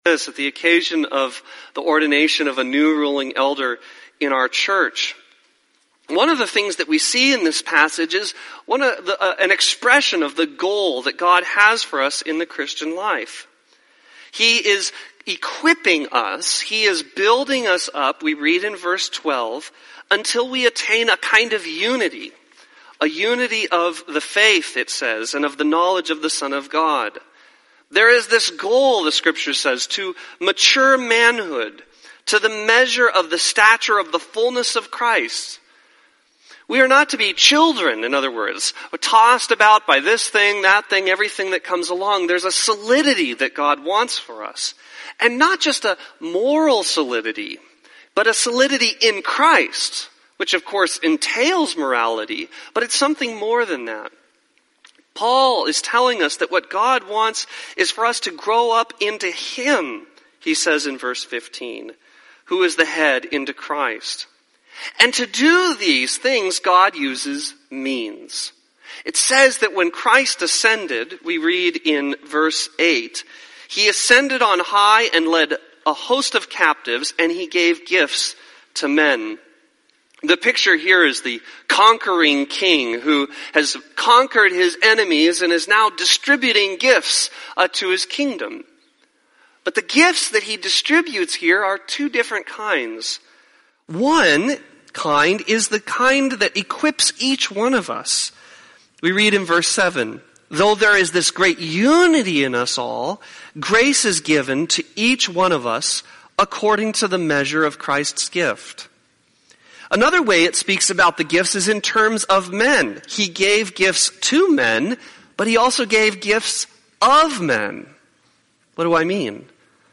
Teaching on the Office of Ruling Elder (Ephesians 4:1-16)